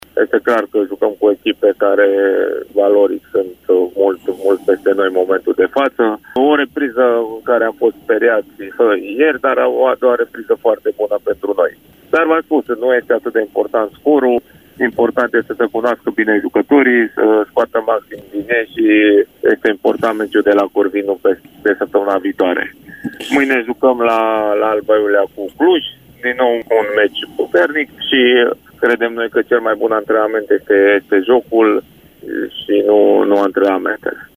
a vorbit la Radio Timișoara despre aceste două jocuri: